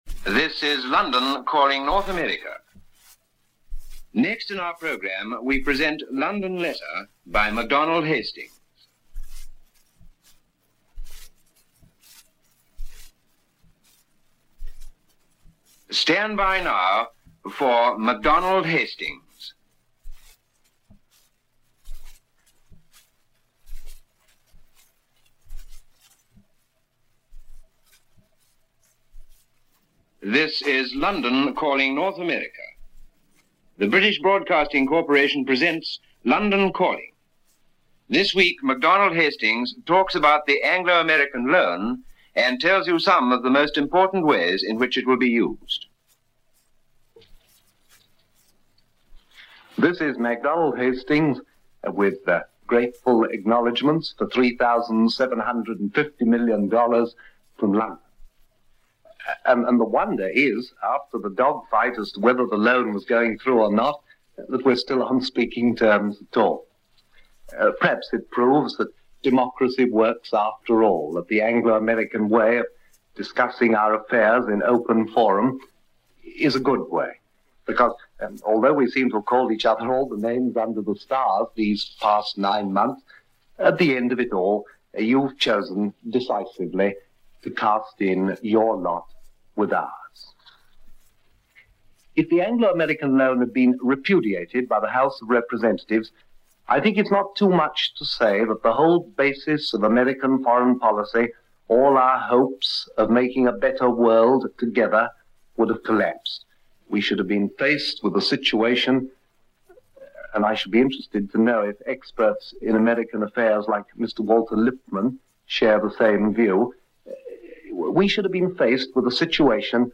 The Anglo-American Loan Of 1946 - Past Daily After Hours Reference Room - MacDonald Hastings editorial - BBC World Service.